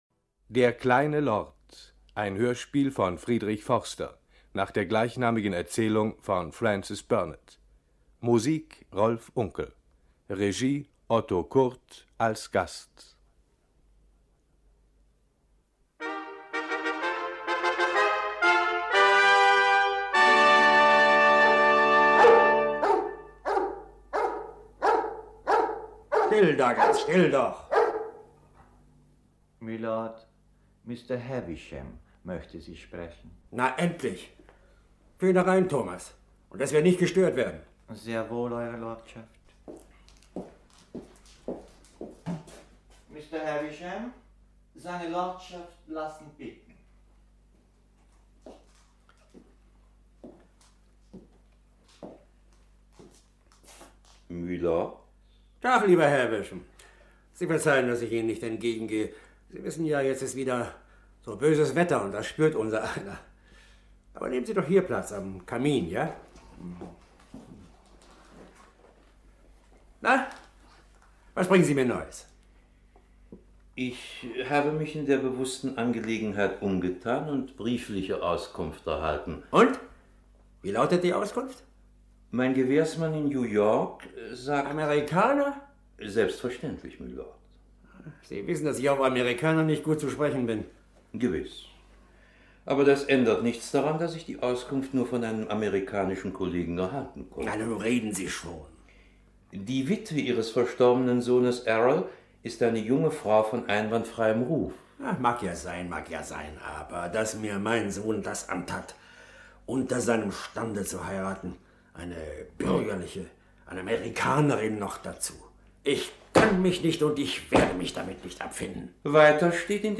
Frank Elstner (Sprecher)